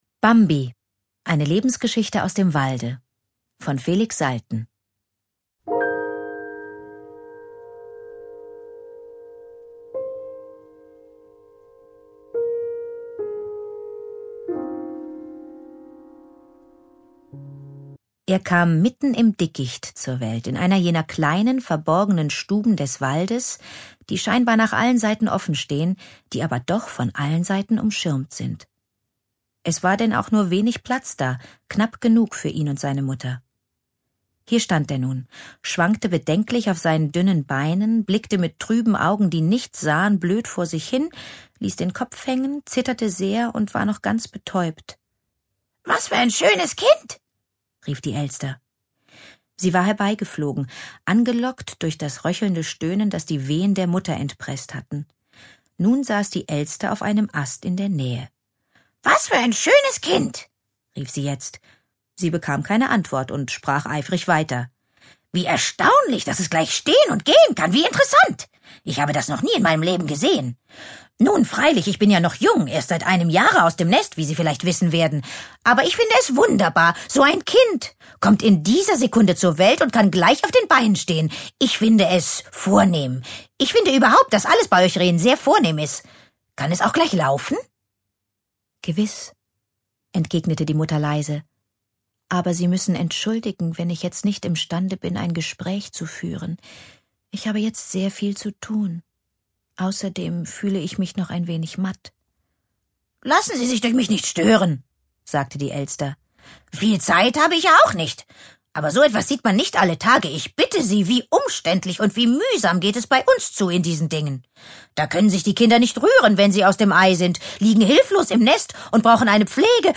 Anke Engelke (Sprecher)
Schlagworte Faline • Gobo • Hörbuch; Lesung für Kinder/Jugendliche • Hörbuch; Literaturlesung • Klassiker • Neuübersetzung • Reh; Kinder-/Jugendliteratur • Walt Disney